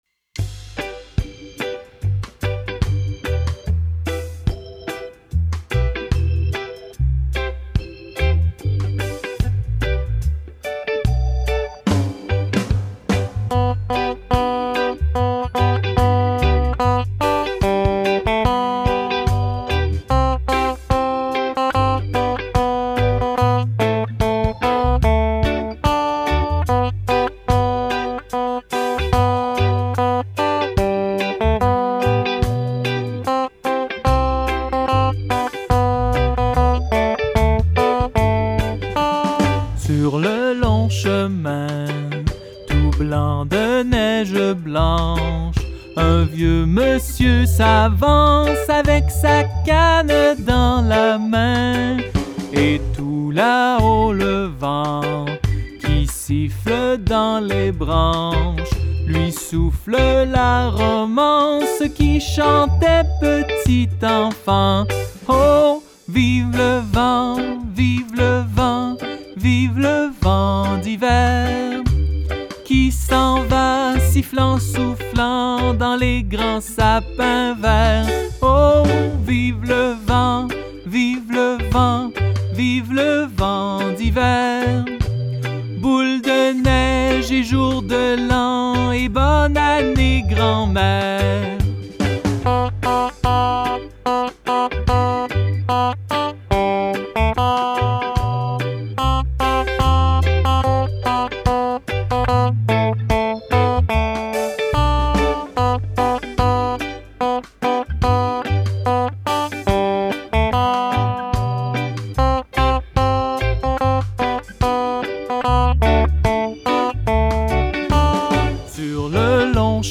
VIVE LE VENT GUITARE